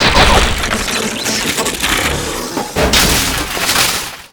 metalprocess.wav